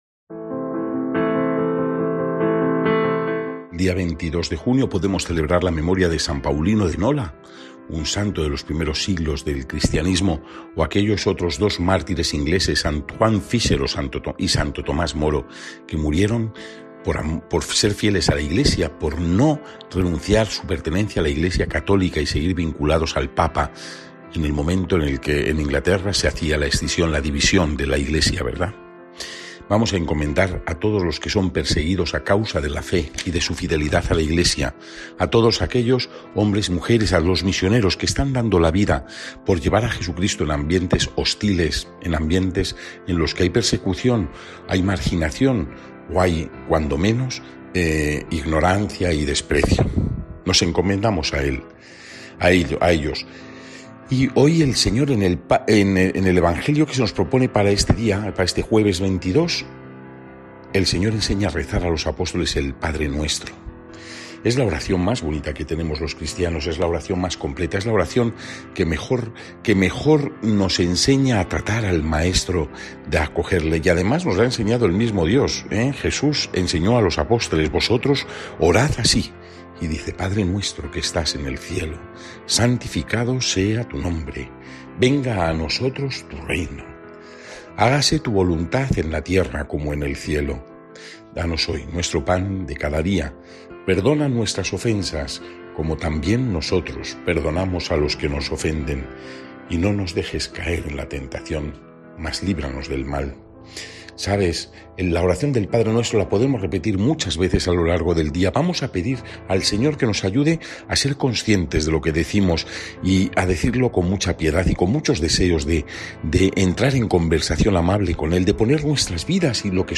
Evangelio según san Mateo (6,7-15) y comentario